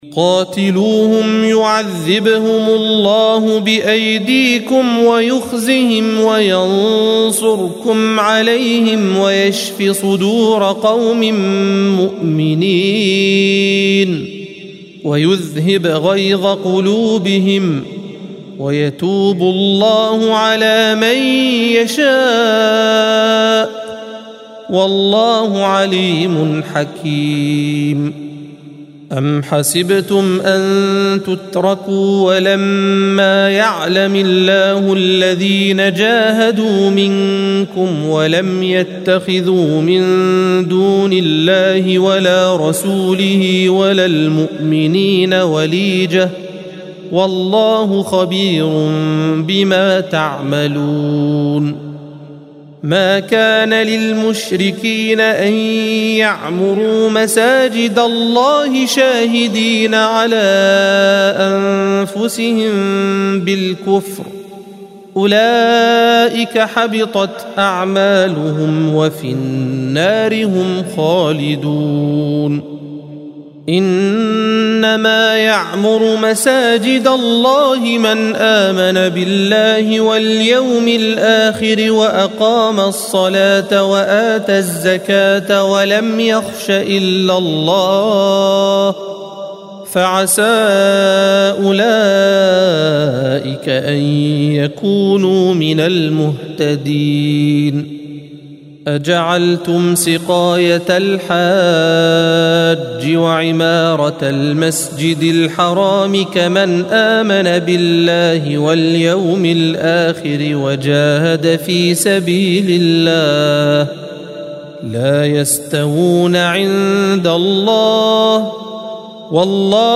الصفحة 189 - القارئ